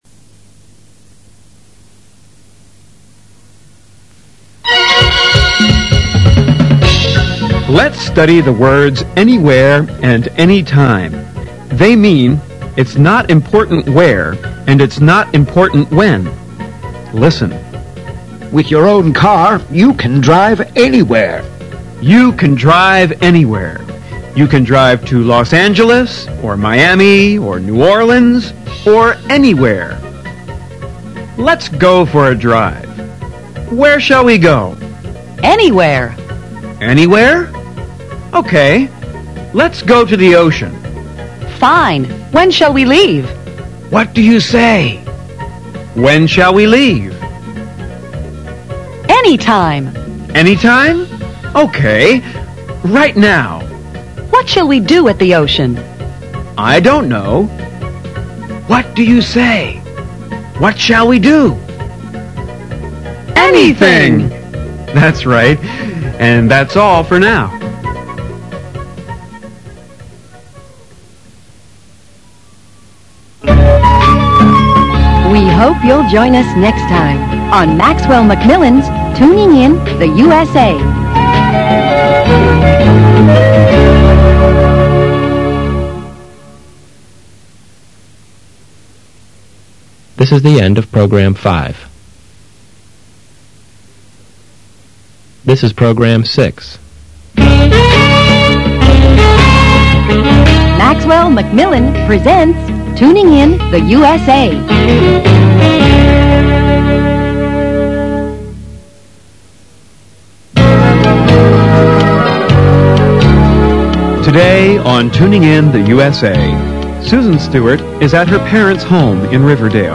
Tuning in the U.S.A (Audiobook) 1-9b